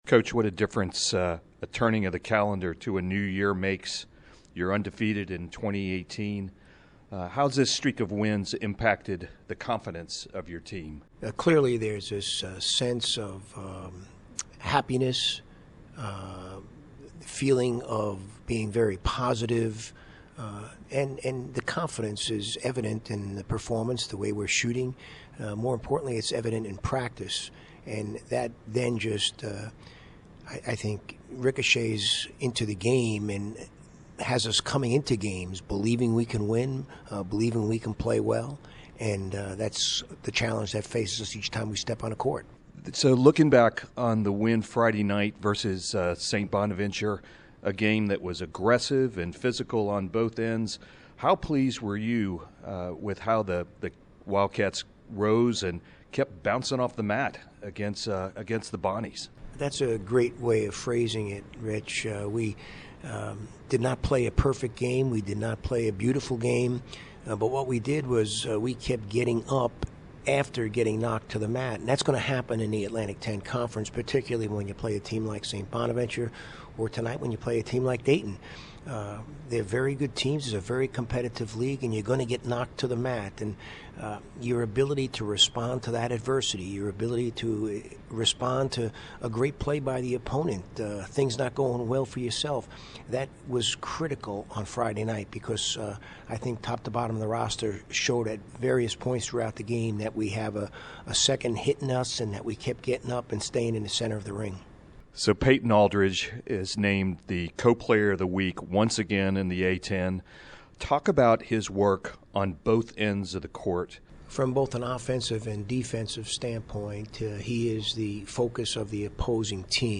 Pregame Interview
Pregame Dayton.mp3